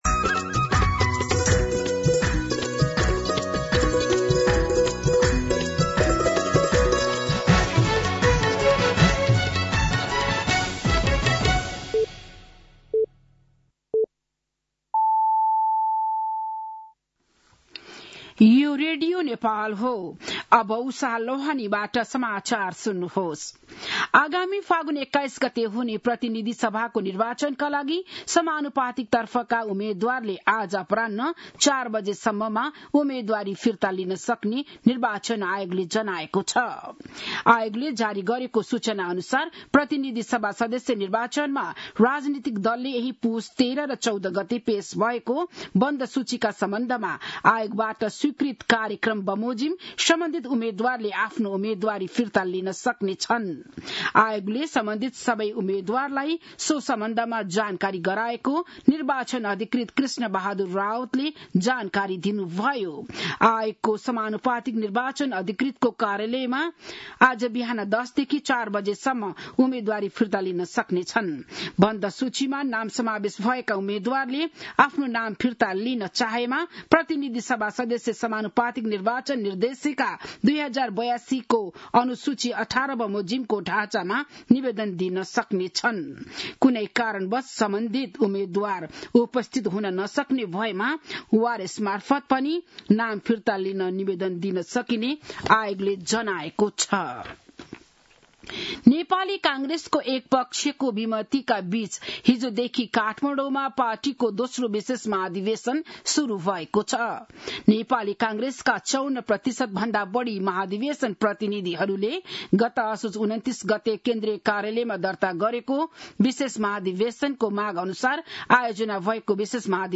बिहान ११ बजेको नेपाली समाचार : २८ पुष , २०८२